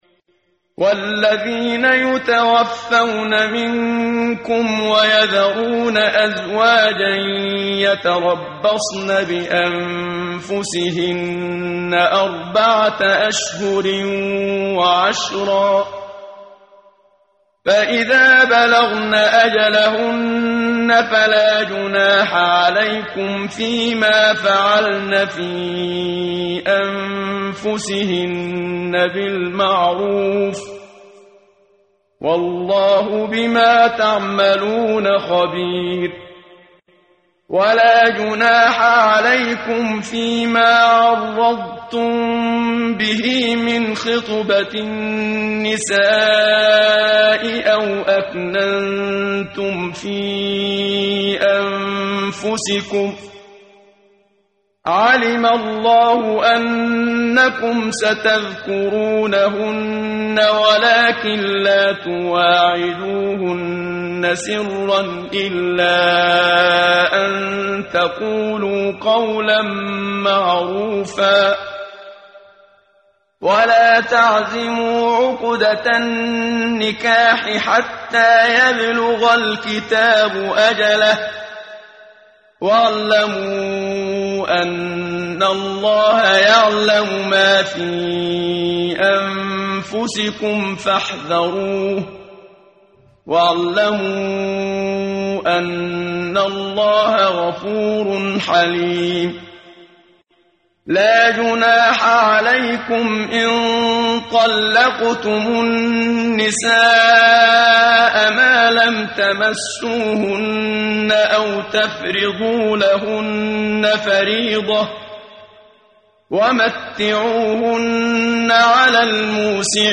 ترتیل
ترتیل صفحه 38 سوره مبارکه بقره (جزء دوم) از سری مجموعه صفحه ای از نور با صدای استاد محمد صدیق منشاوی